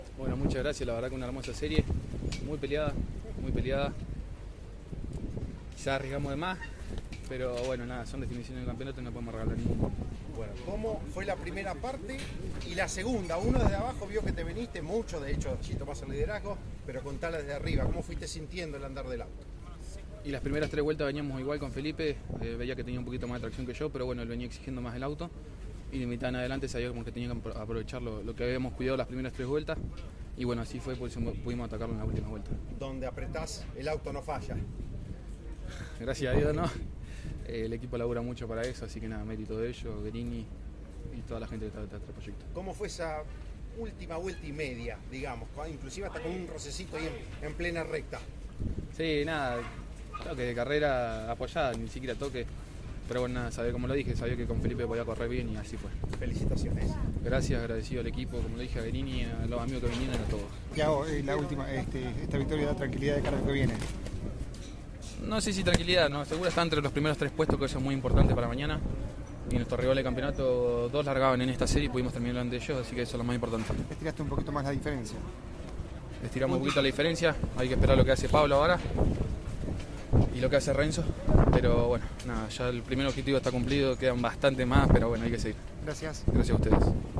Al término de los parciales, los protagonistas dialogaron con CÓRDOBA COMPETICIÓN.